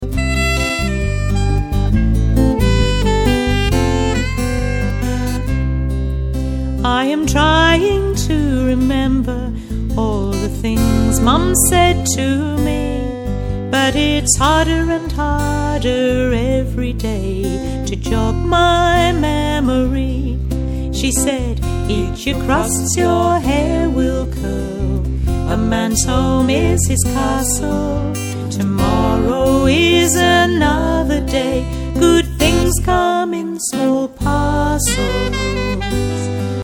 narration
linking songs